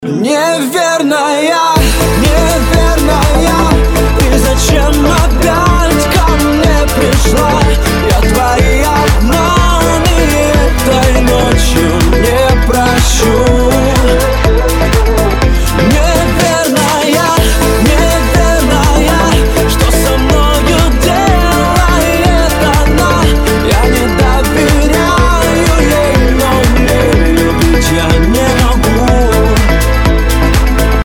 поп
мужской вокал
dance
vocal